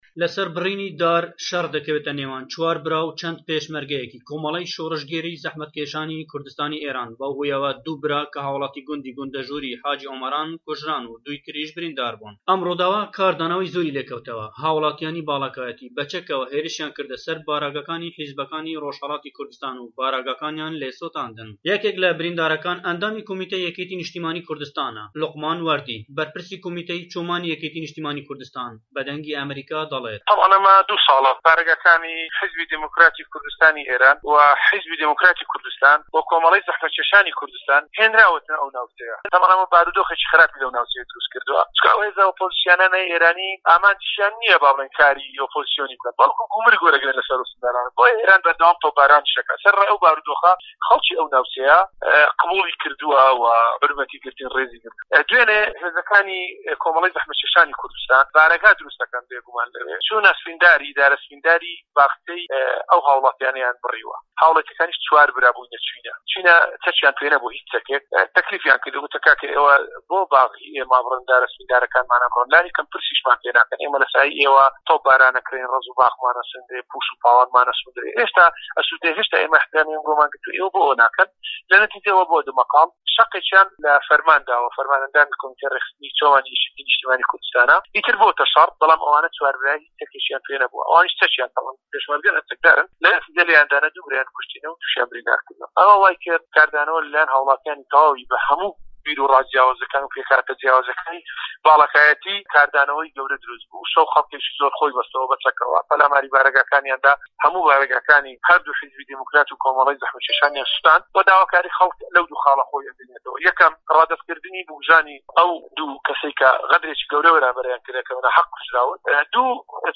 ڕاپۆرت سه‌باره‌ت به‌ ڕووداوی گونده‌ژوور